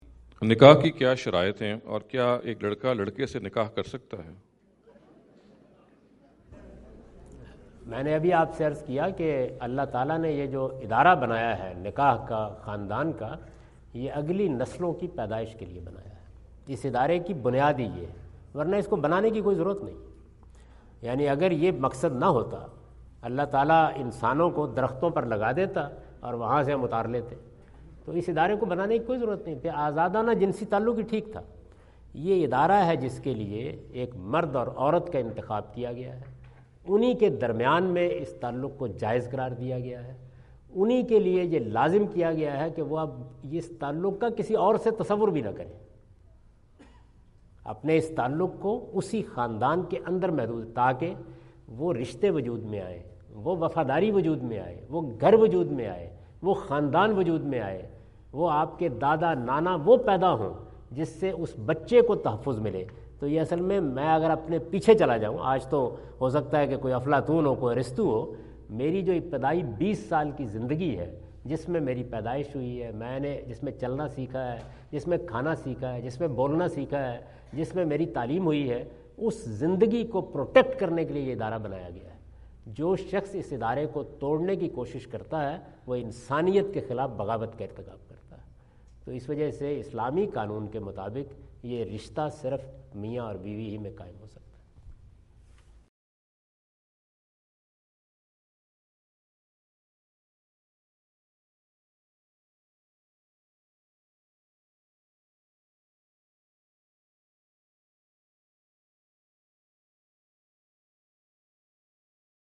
Javed Ahmad Ghamidi answer the question about "Conditions of Nikah" asked at Aapna Event Hall, Orlando, Florida on October 14, 2017.